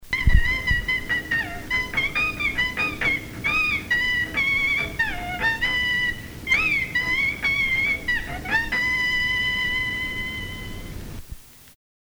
échantillons musicaux pour la soutenance de thèse
Pièce musicale inédite